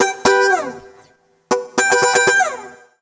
120FUNKY17.wav